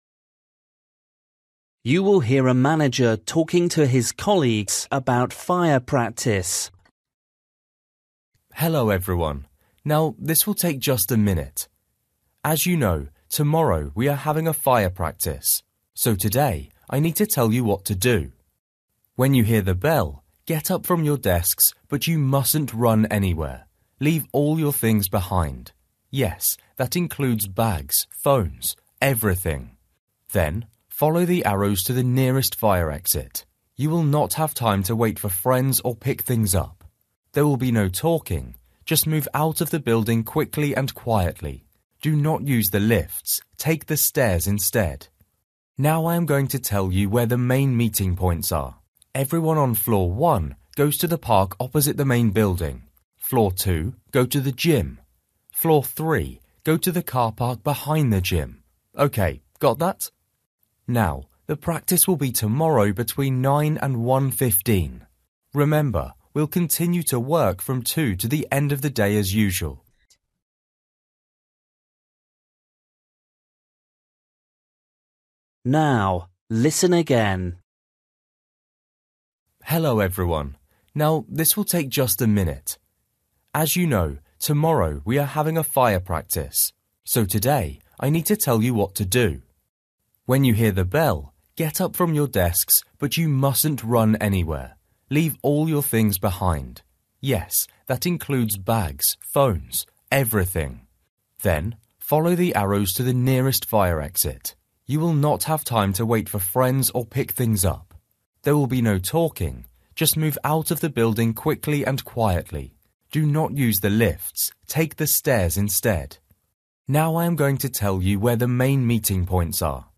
You will hear a manager talking to his colleagues about fire practice.